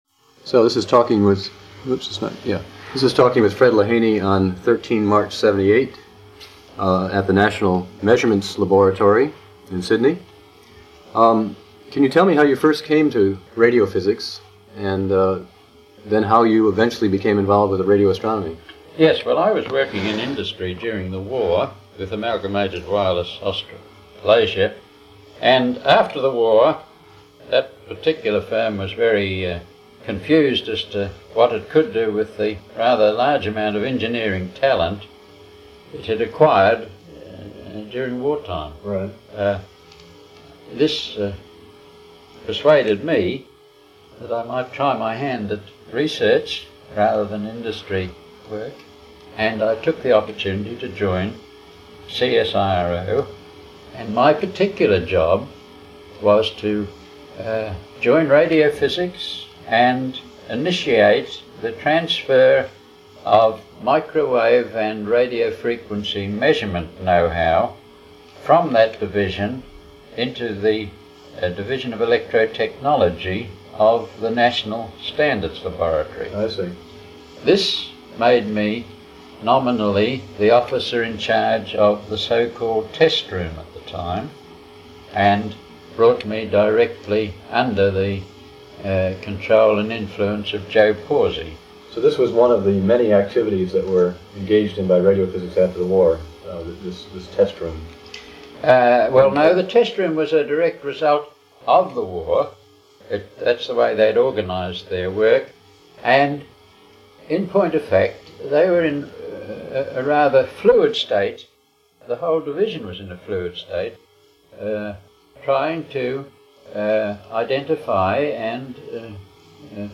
Type Oral History
Original Format of Digital Item Audio cassette tape